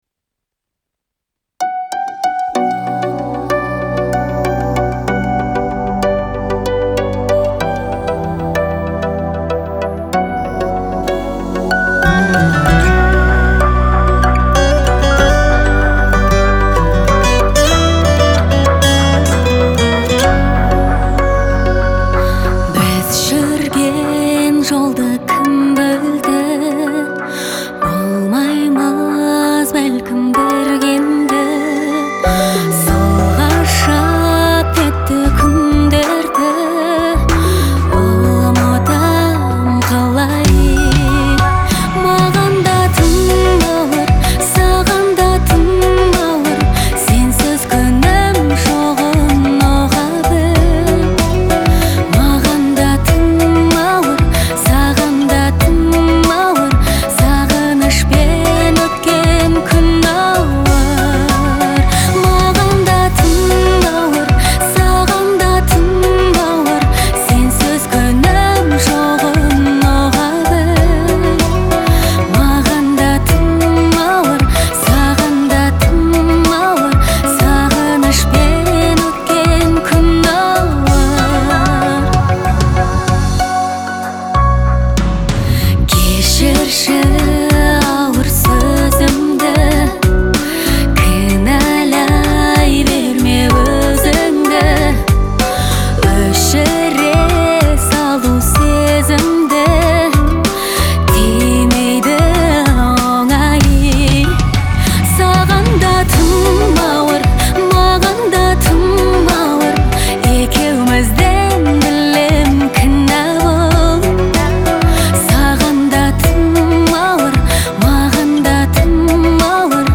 это трогательная и меланхоличная песня казахстанской певицы
выполненная в жанре поп.